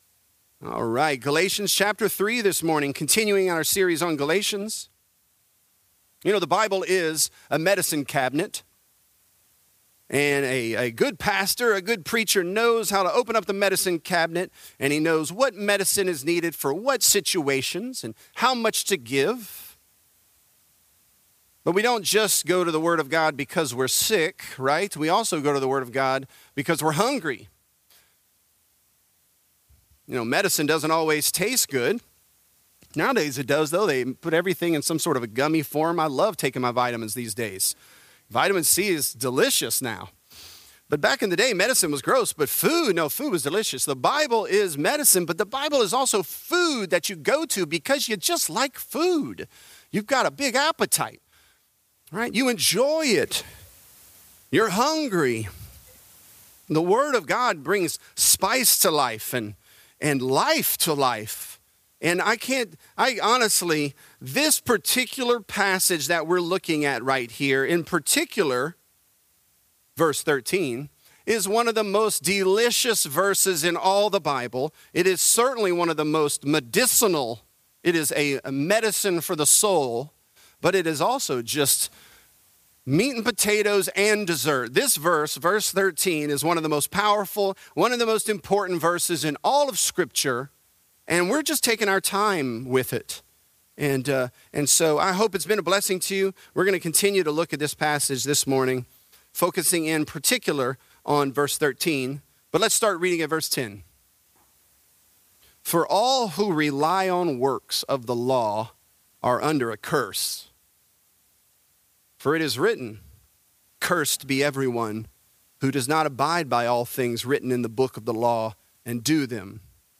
Galatians: The Curse | Lafayette - Sermon (Galatians 3)